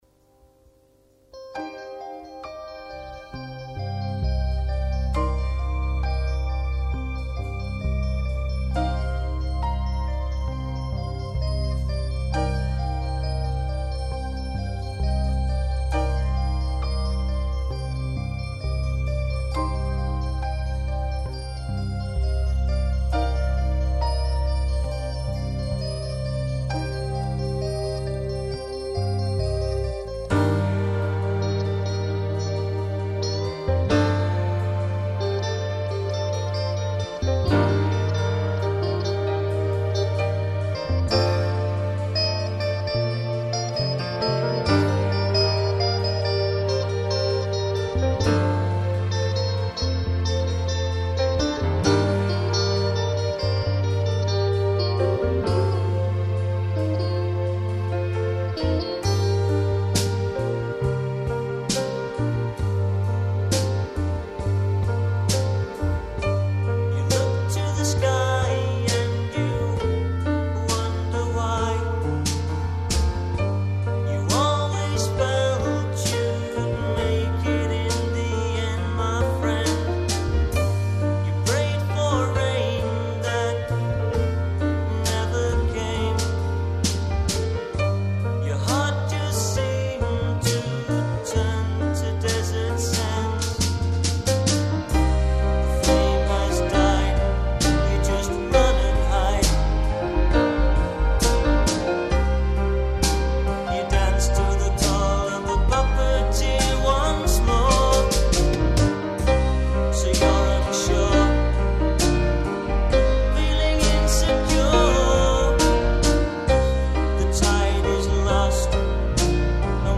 keys
vocals
guitar and bass